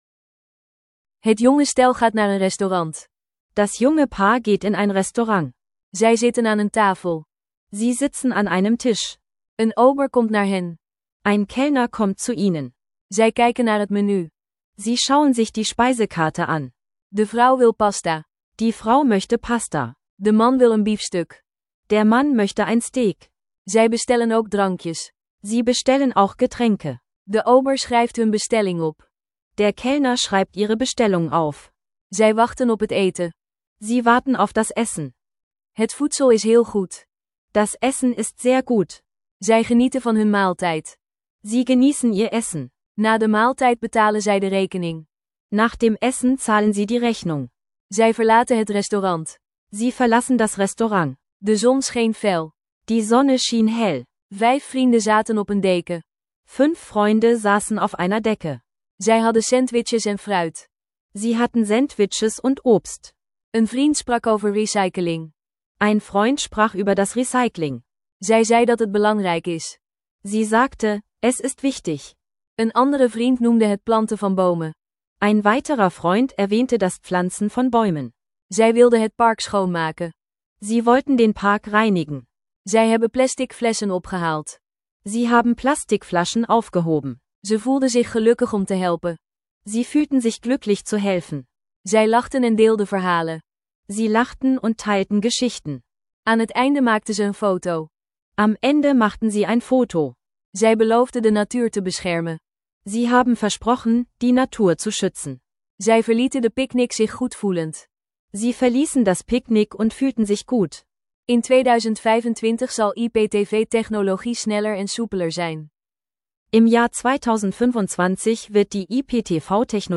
Konversation zwischen einem jungen Paar und dem Kellner erkunden.